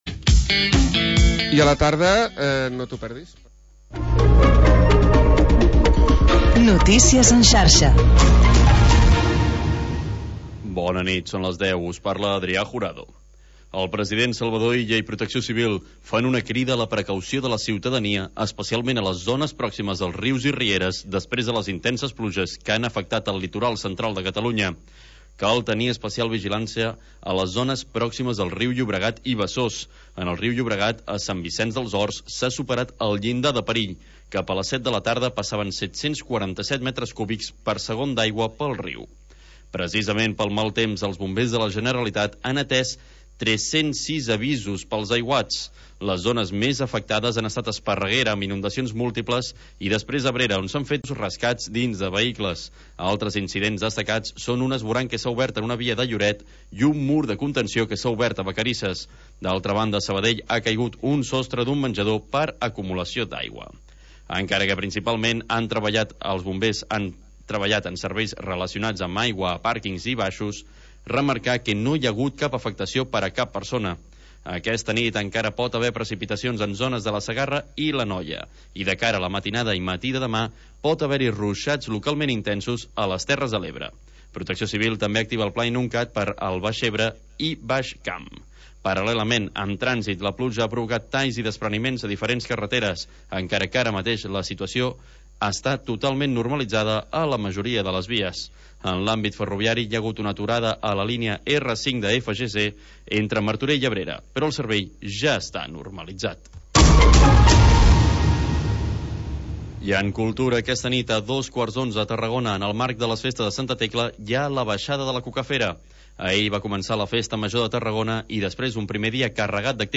Selecció musical de Dj.